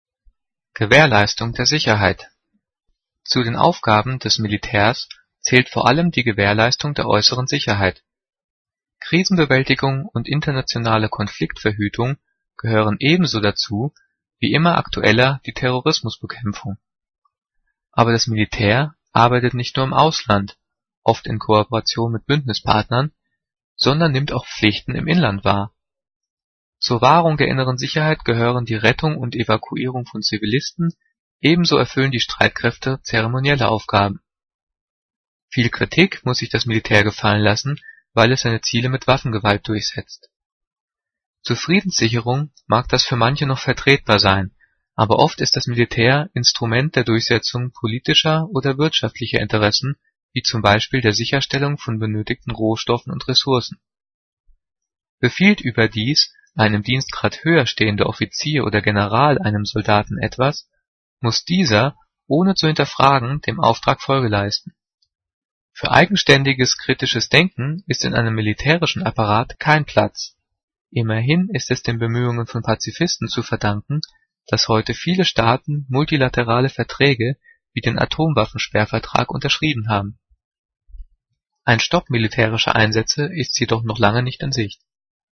Gelesen:
gelesen-gewaehrleistung-der-sicherheit.mp3